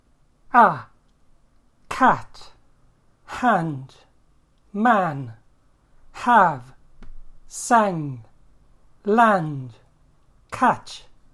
æ
cat, hand, man, have, sang, land, catch
æ.mp3